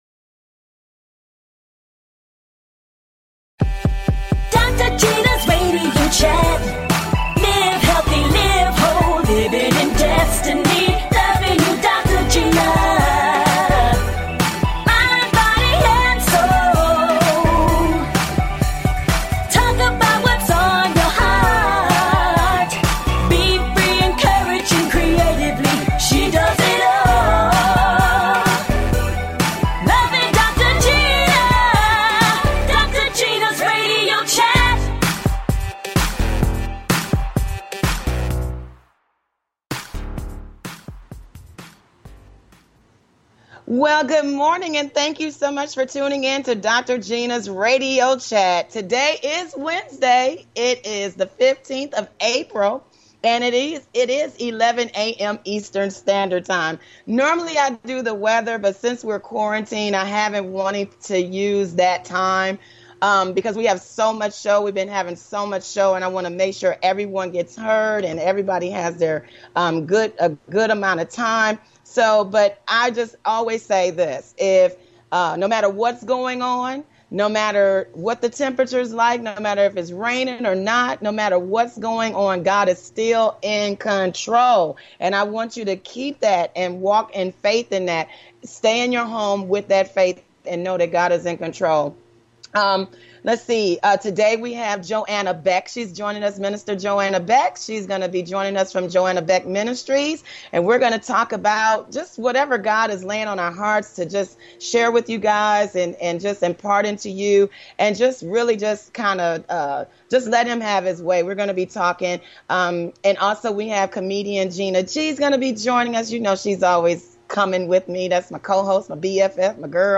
Fun! Exciting! And full of laughter!